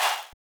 chant1.wav